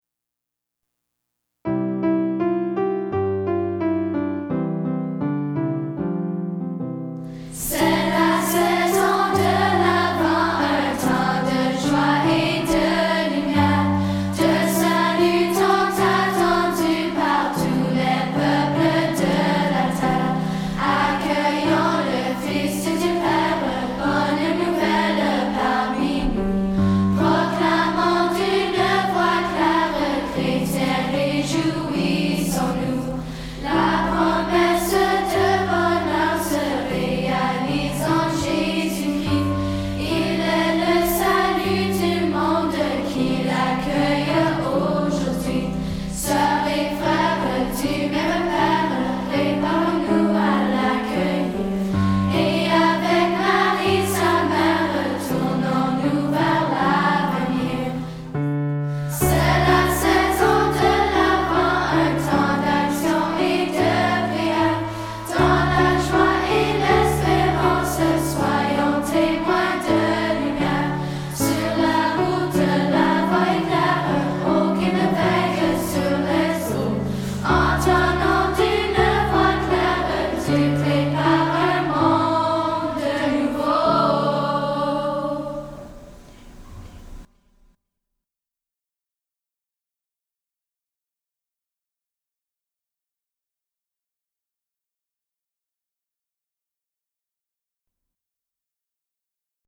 Chants divers
Les voyelles soulignées sont chantées.
hymne_a_l_avent_chant.mp3